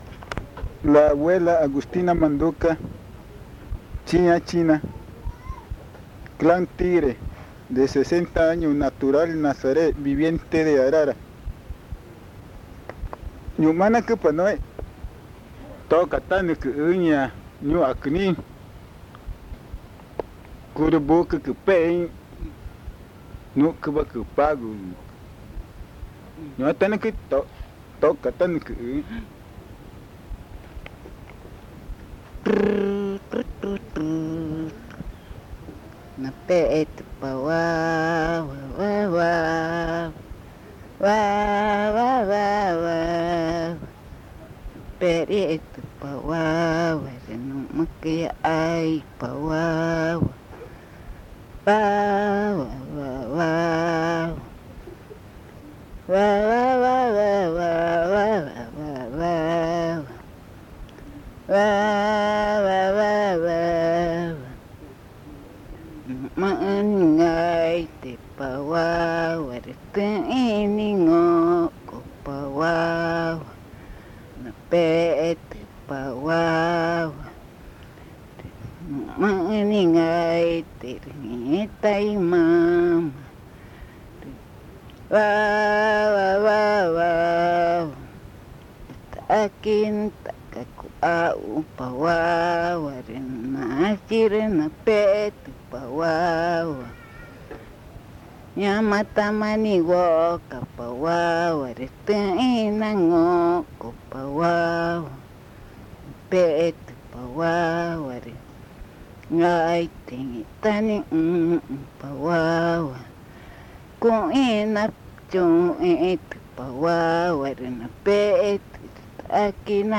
Arrullo con diablo
Arara, Amazonas (Colombia)
sings a lullaby in Magütá